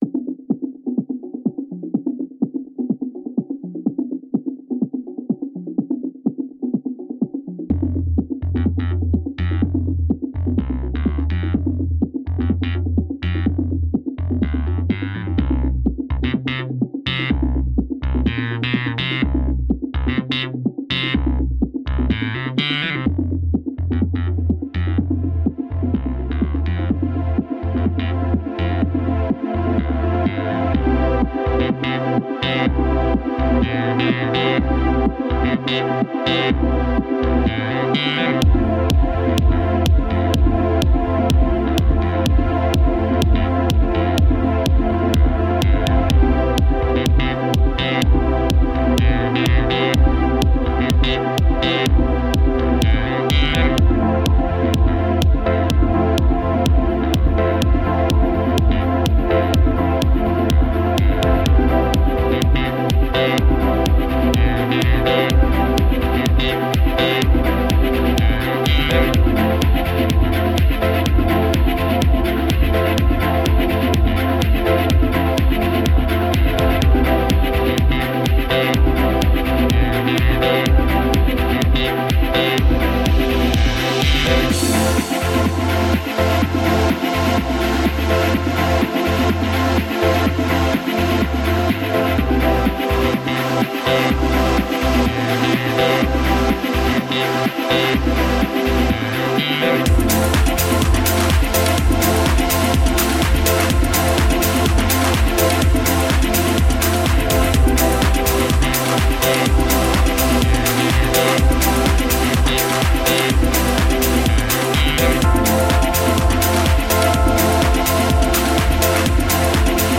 A summer cocktail of chill out, electronica and ambient.
Tagged as: Electronica, Other, Chillout